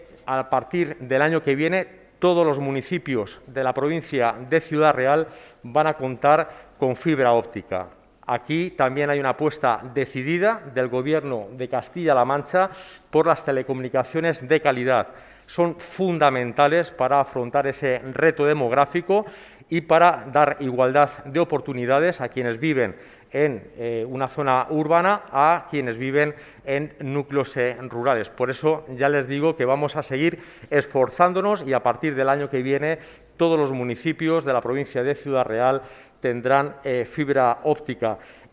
Lo ha hecho durante la rueda de prensa que ha tenido lugar en la Diputación de Ciudad Real, y en la que ha comparecido junto al presidente de esta institución provincial, José Manuel Caballero; la alcaldesa de la capital, Pilar Zamora; el viceconsejero de Medio Ambiente, Fernando Marchán; el director general de Cohesión Territorial, Alipio García; la delegada de la Junta en Ciudad Real, Carmen Teresa Olmedo; y el delegado provincial de Desarrollo Sostenible, Fausto Marín.